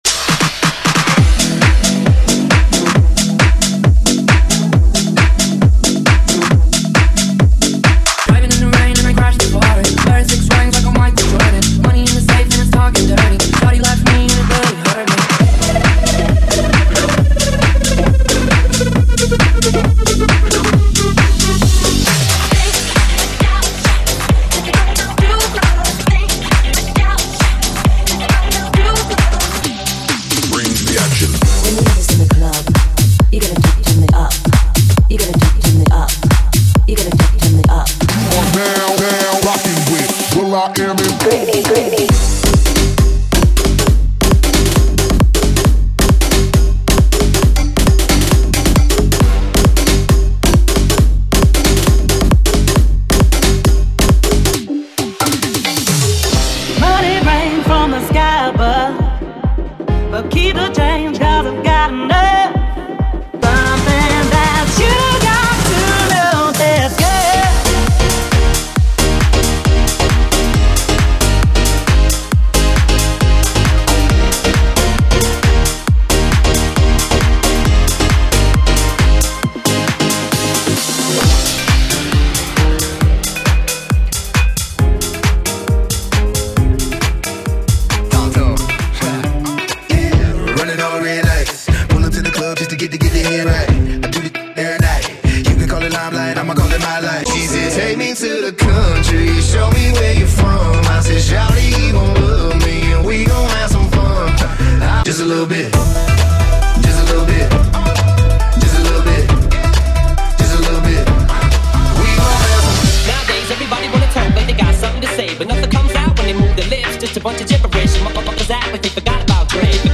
MegaSounds! Club, House & some Booty Beats.
BPM: 135 [56:00] FORMAT: 32COUNT